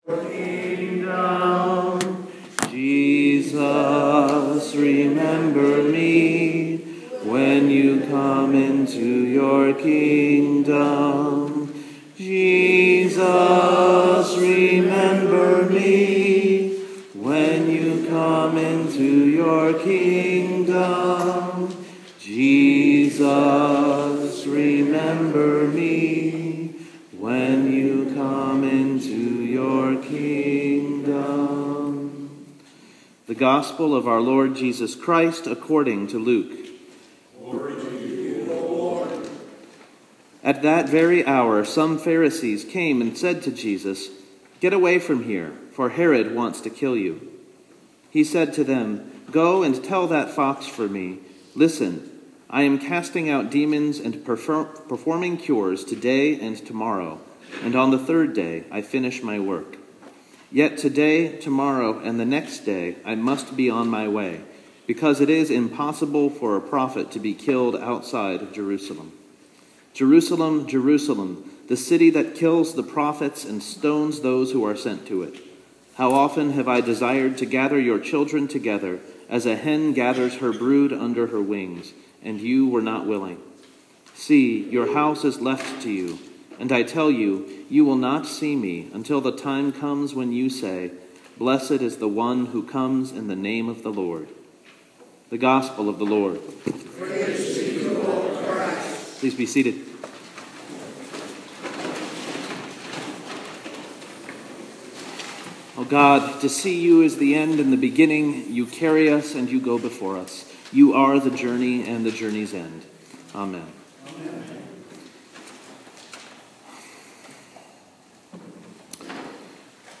The text is Luke 13:31-35. Here is the sermon: